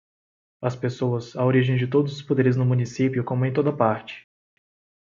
Read more Frequency B2 Pronounced as (IPA) /oˈɾi.ʒẽj̃/ Etymology Borrowed from Latin origo In summary Borrowed from Latin originem.